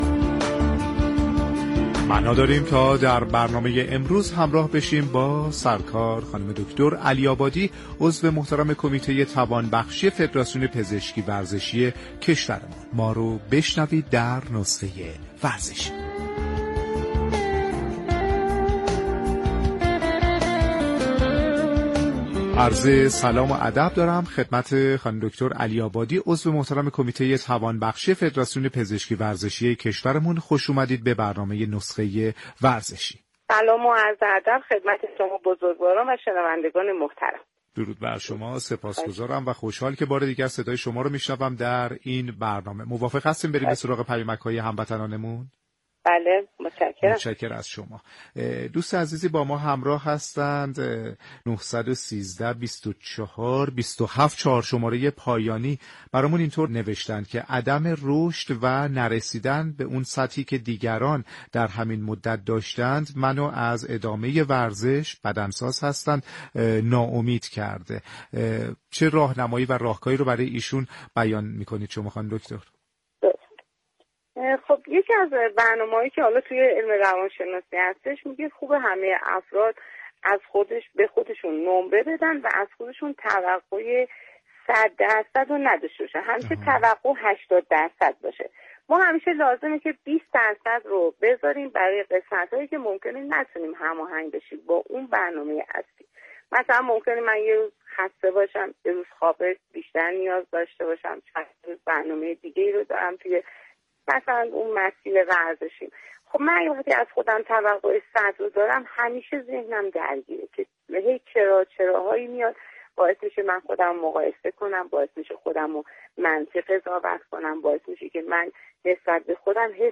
در گفت وگو با برنامه نسخه ورزشی رادیو ورزش.